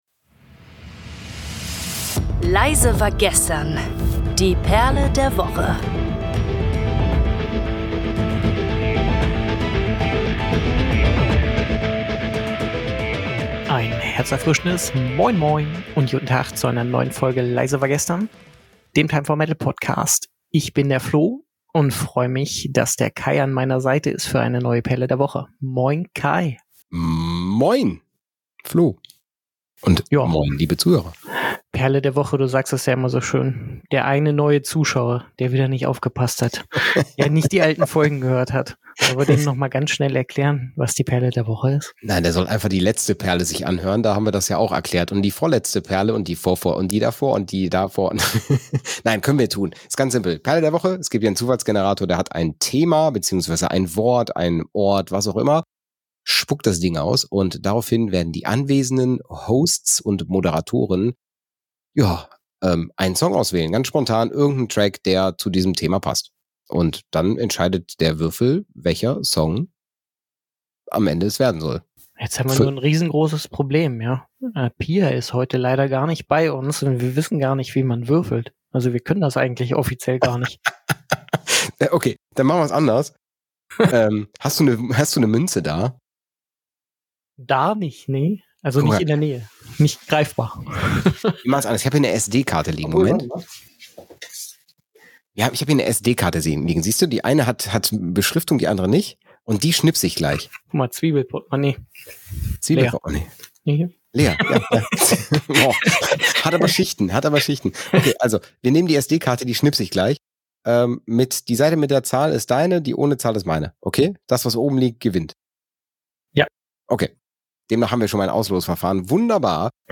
Zu Beginn der kurzweiligen Episoden sucht sich der Zufallsgenerator ein Thema raus, welches dann als Basis für jeweils eine Empfehlung der Moderatoren herangezogen wird.